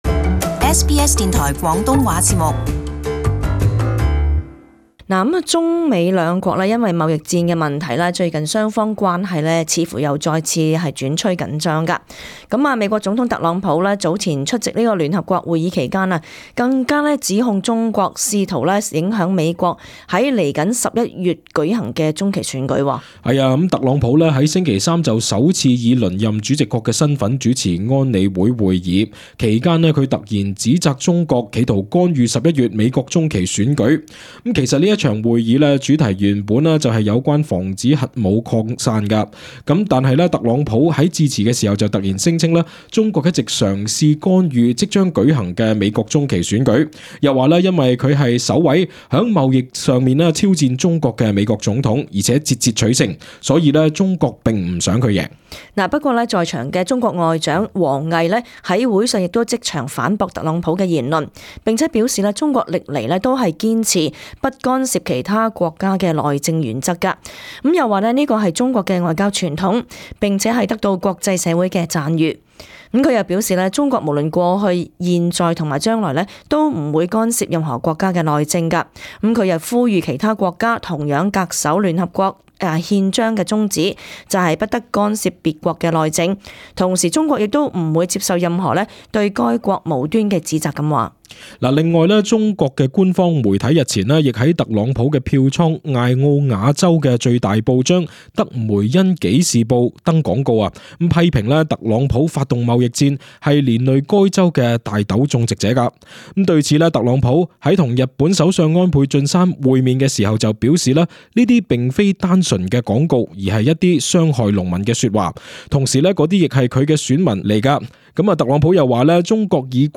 【時事報導】中美貿易戰持續